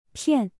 (piàn) — cheat